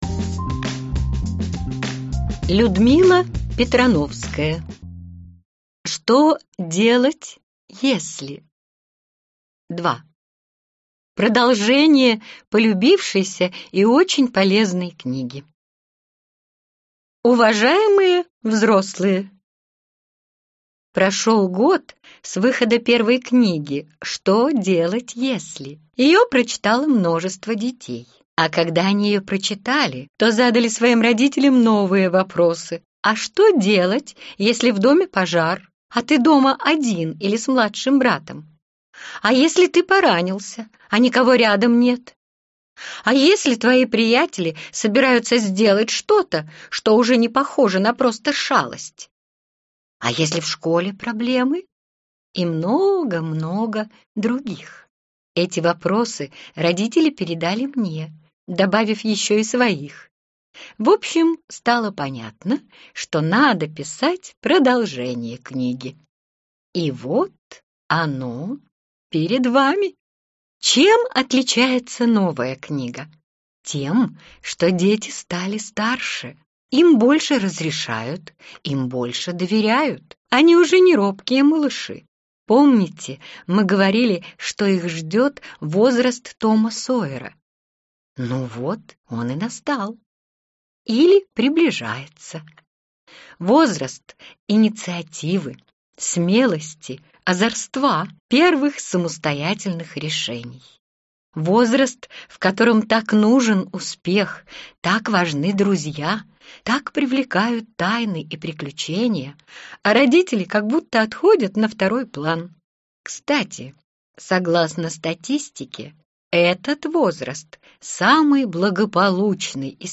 Аудиокнига Что делать, если… 2. Продолжение полюбившейся и очень полезной книги | Библиотека аудиокниг